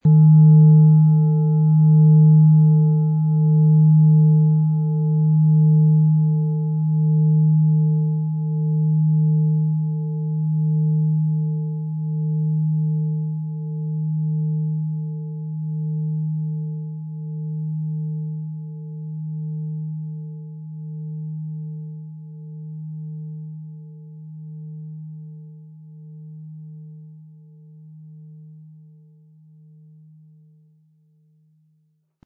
• Tiefster Ton: Wasserstoffgamma
PlanetentöneThetawelle & Wasserstoffgamma
MaterialBronze